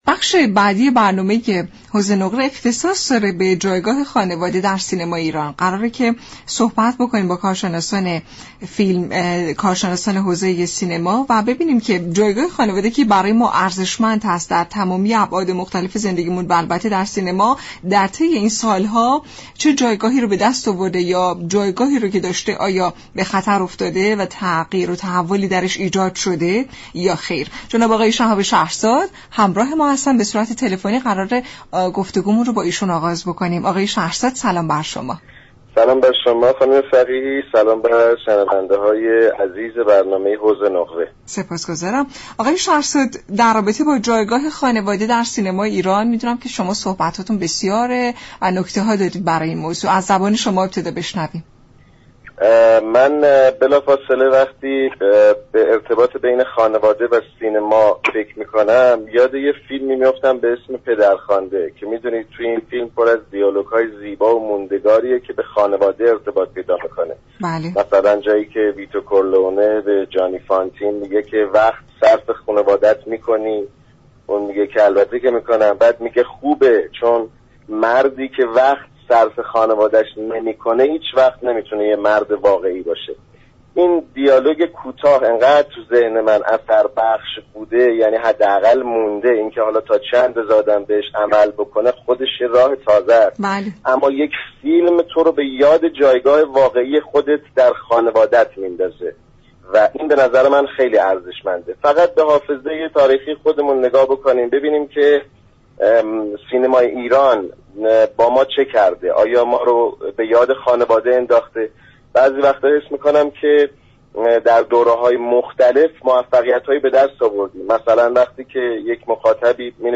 به گزارش شبكه رادیویی ایران، یك فیلمساز و منتقد سینما درباره جایگاه خانواده در سینمای ایران به برنامه «حوض نقره» گفت: در حالی كه سینمای ایران در دهه 60 شاهد اوج گیری فیلم های خانوادگی بود، این روزها سینمای ایران به سمت تولید فیلم هایی می رود كه خانواده در آنها نادیده گرفته شده است.